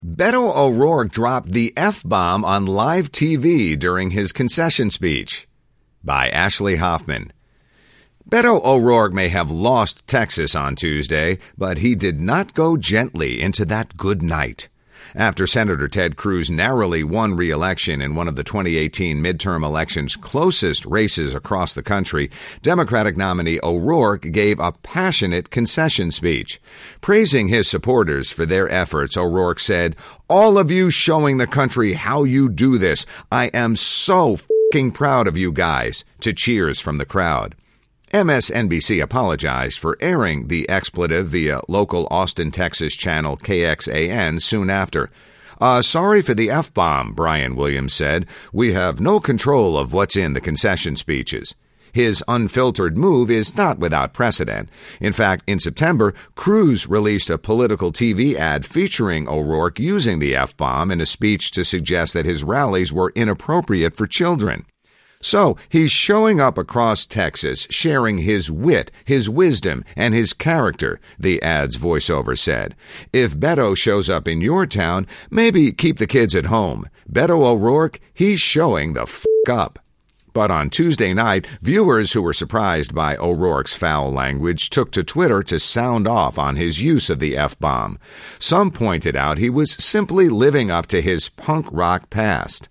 After Sen. Ted Cruz narrowly won re-election in one of the 2018 midterm election's closest races across the country, Democratic nominee O'Rourke gave a passionate concession speech.